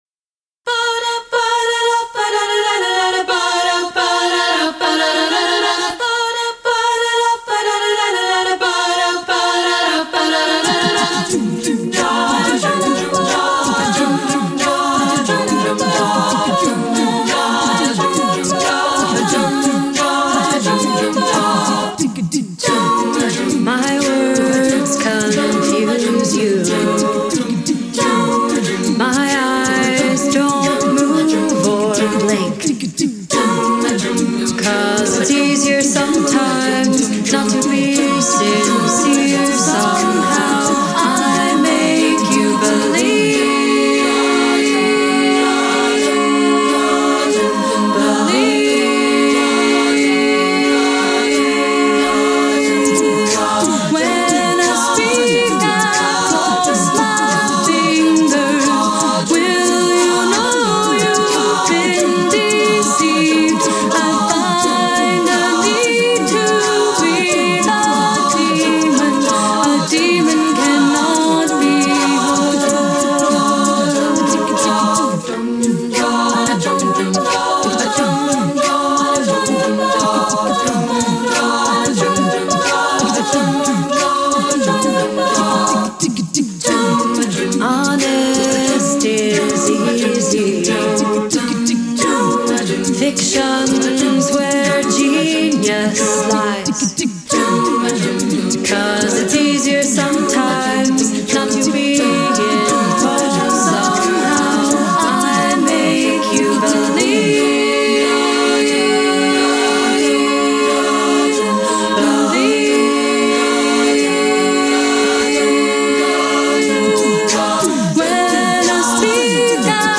soloists